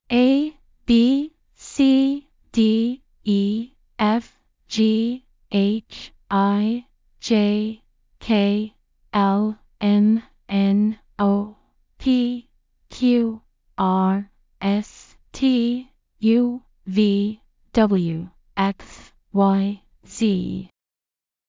アルファベット２６文字の音声（ゆっくり）：©音読さん
alphabetslow.mp3